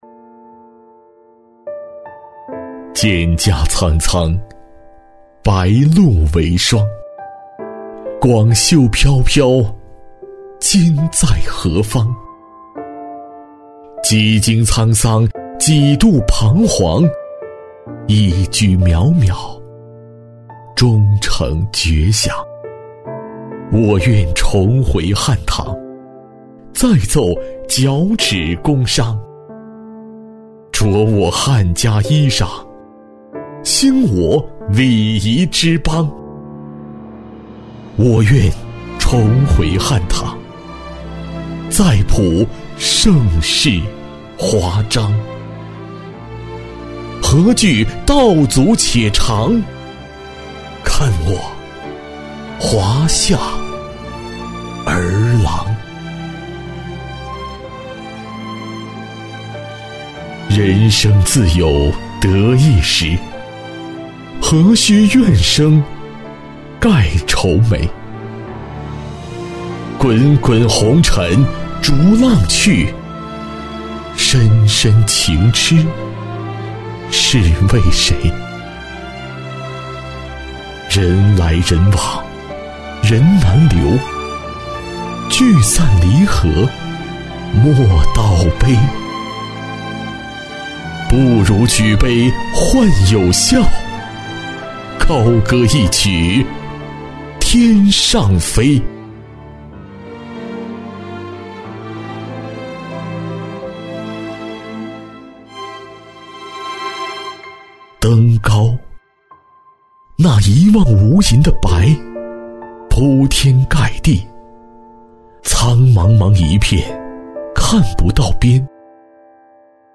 朗诵配音